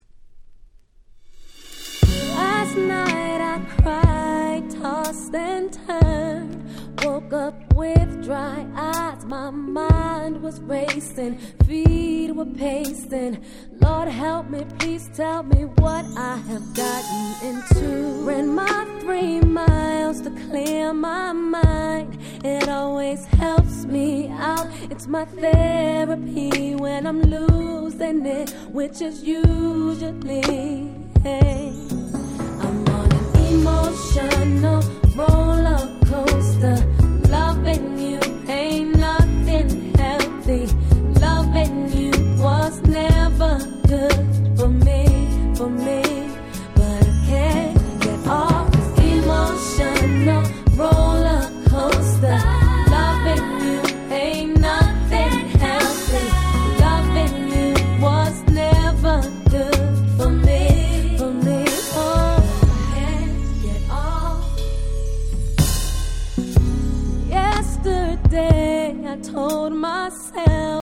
02' Very Nice Neo Soul / R&B !!